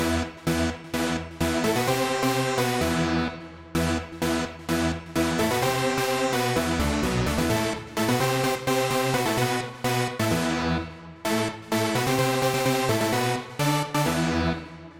Tag: 128 bpm Dance Loops Synth Loops 2.52 MB wav Key : Unknown